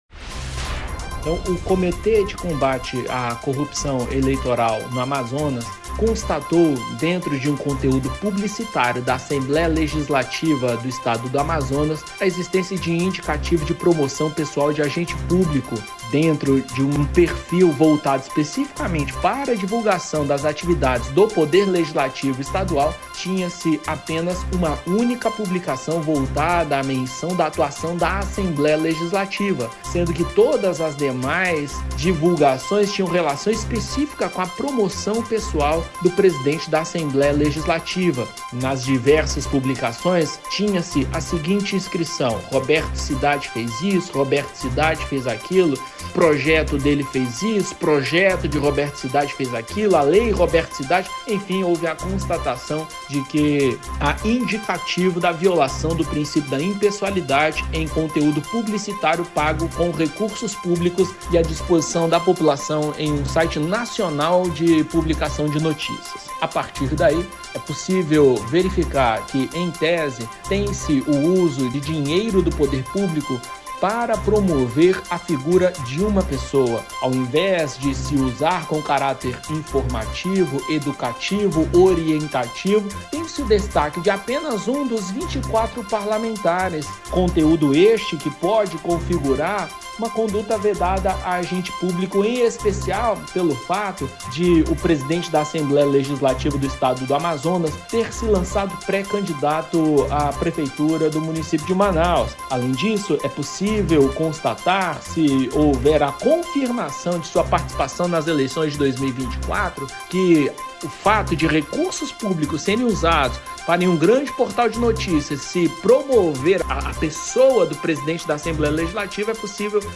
Conforme o promotor de justiça do MPAM, Weslei Machado, a denúncia contra Roberto Cidade apresenta um agravante, que é o possível dano ao erário público. (ouça abaixo)
SONORA-PROMOTOR-WESLEI-MACHADO-RIO-MAR.mp3